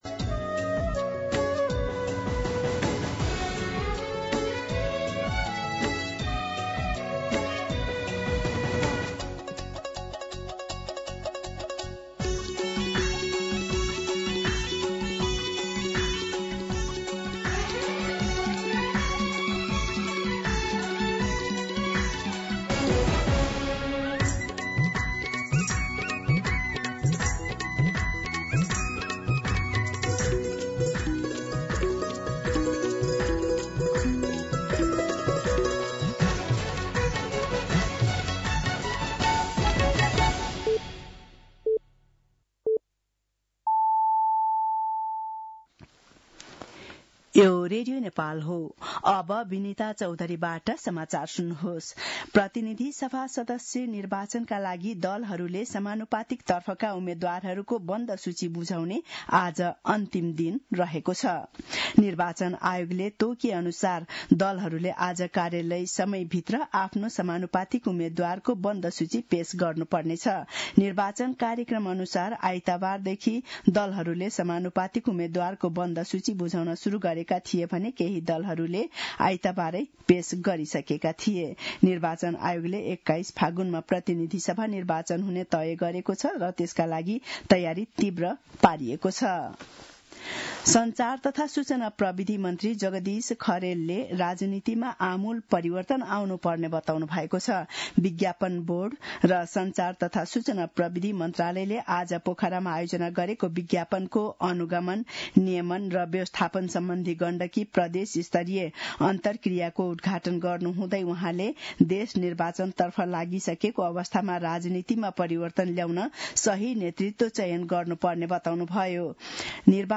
An online outlet of Nepal's national radio broadcaster
दिउँसो १ बजेको नेपाली समाचार : १४ पुष , २०८२